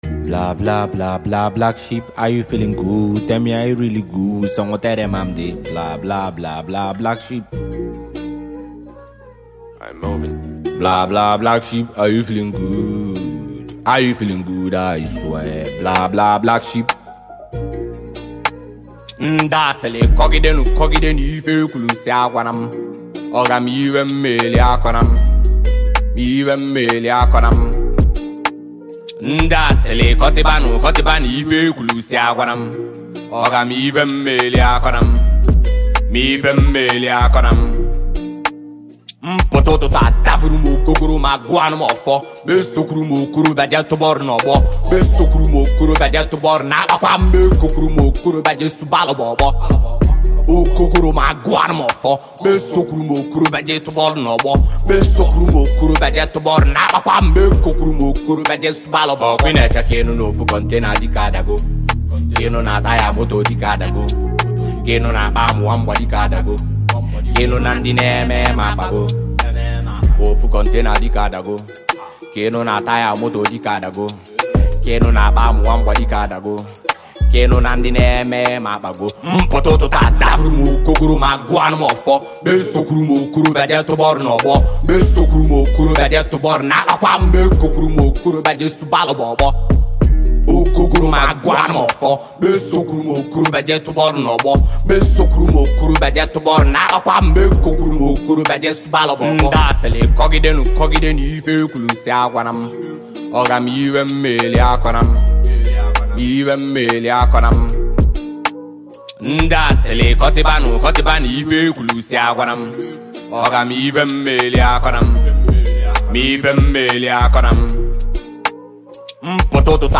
emotional and heartfelt record